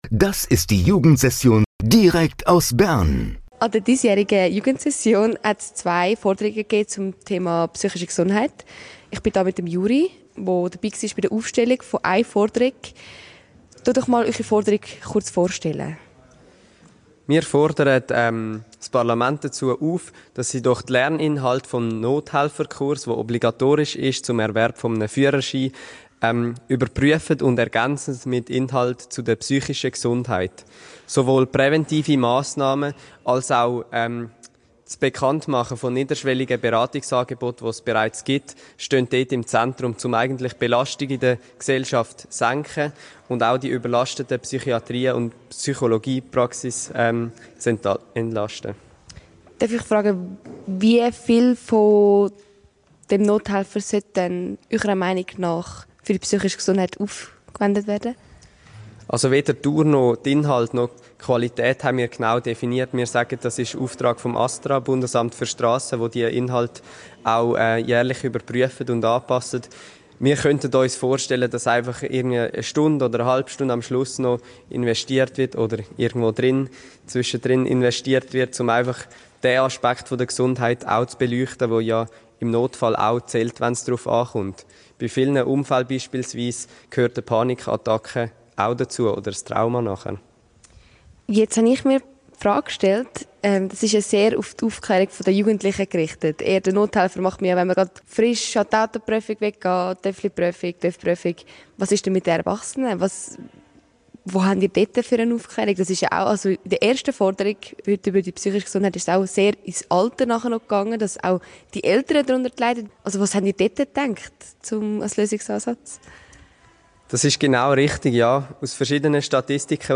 Bei der zweiten Forderung bezüglich psychischer Gesundheit habe ich mir eine Stimme aus den Teilnehmenden geholt.